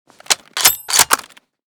k98_unjam.ogg